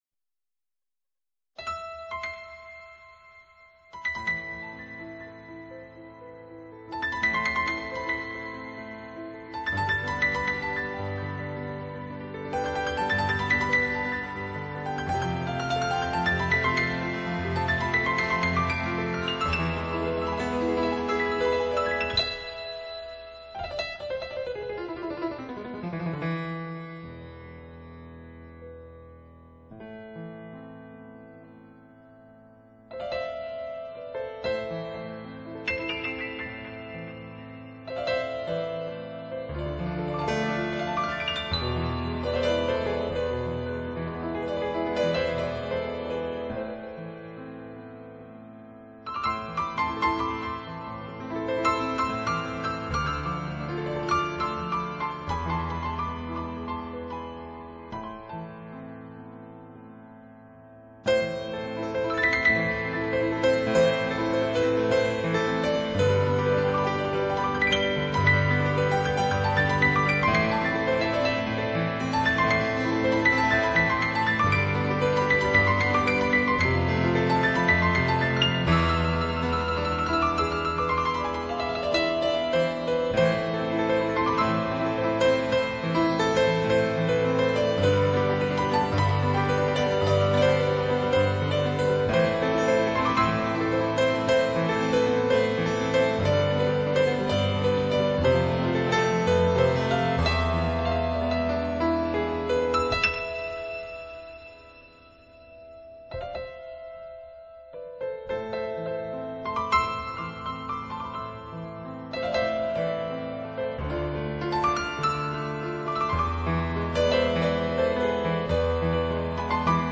类型：New Age
轻柔的吉它、优雅的长笛佐以婉约的钢琴，